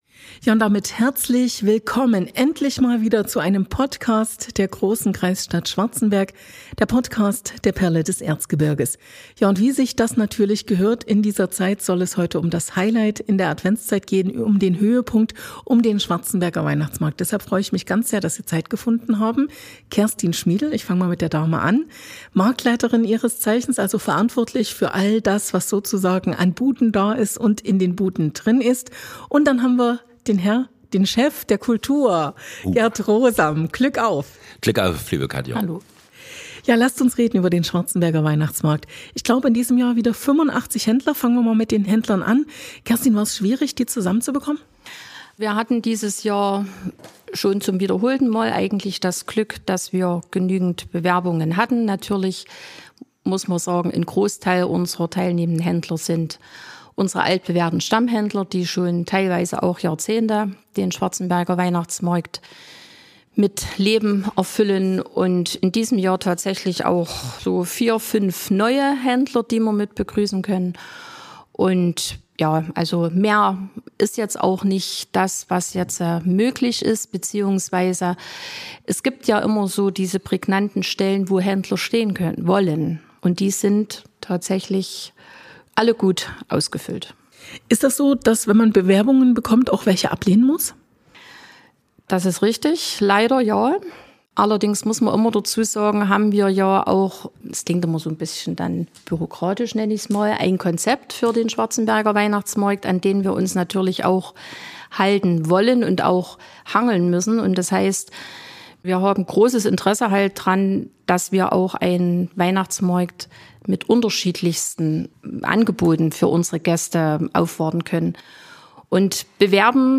Was sind die Höhepunkte? Was erwartet Sie kulinarisch? Und welche Händler sind dabei? Gönnen Sie sich die unterhaltsame Plauderei.